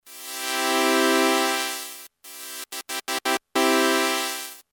今回、カットオフ特性に6dbが加わった。音を丸くしていっても上の方の倍音が残るのでフィルタとしてかなり性能の悪いモノのシミュレーションになるのか？